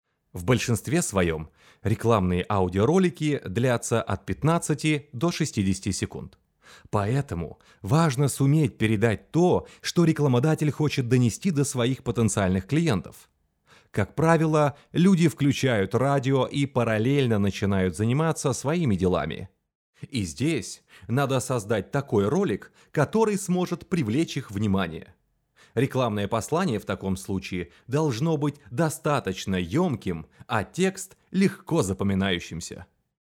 Пример голоса 1
Мужской
Баритон